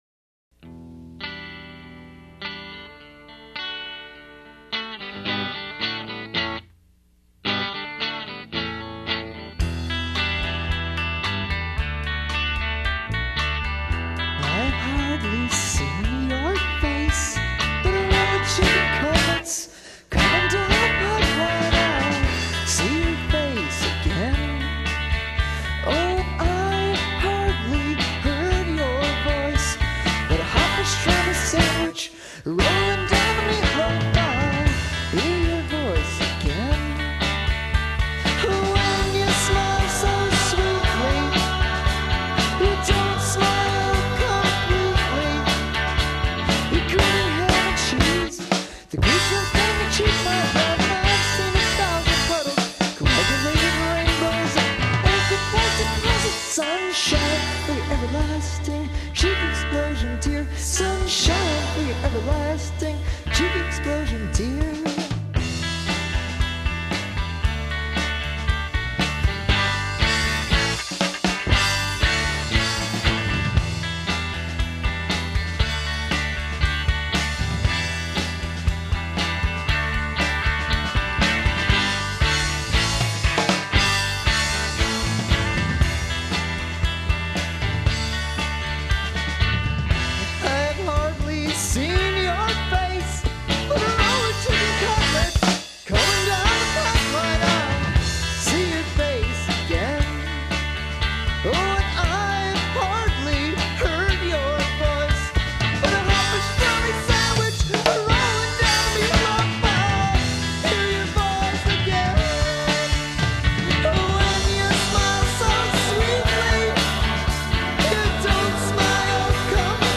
bass
guitar
drums
vocals
rock and roll